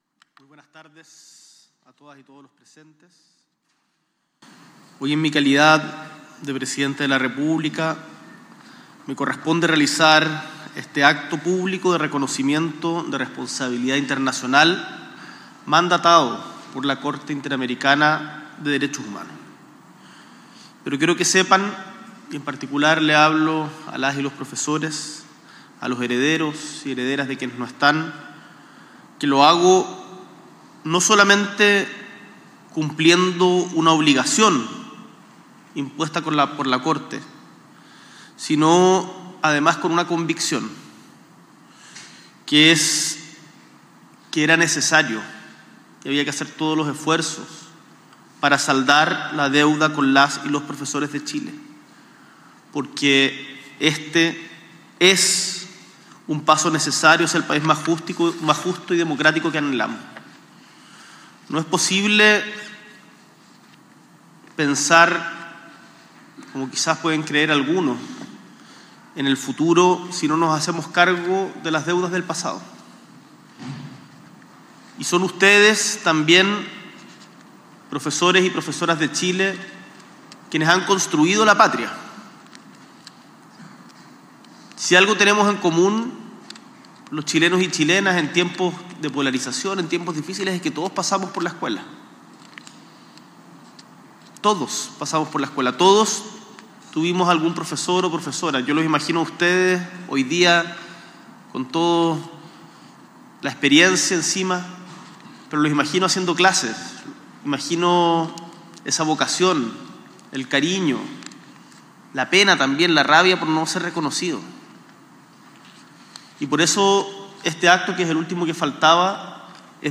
S.E. el Presidente de la República, Gabriel Boric Font, encabeza el acto público de reconocimiento de responsabilidad internacional del Caso profesores de Chañaral y otras municipalidades